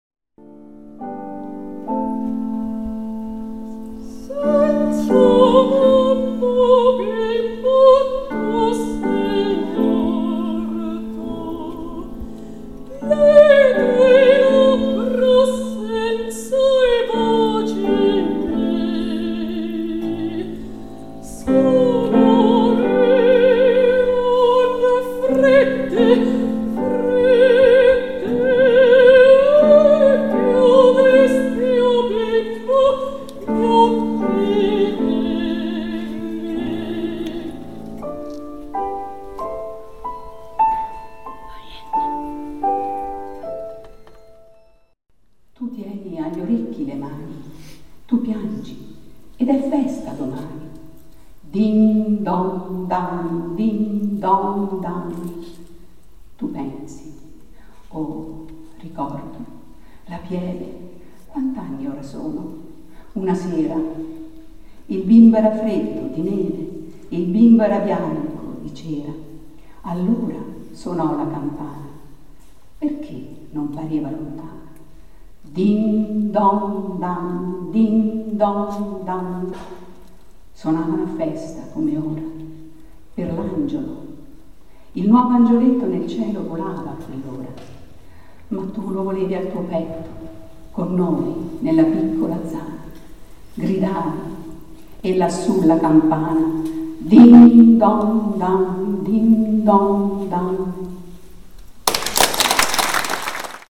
Conservatorio S.Elisabetta
Mezzosoprano
pascoli_poem.mp3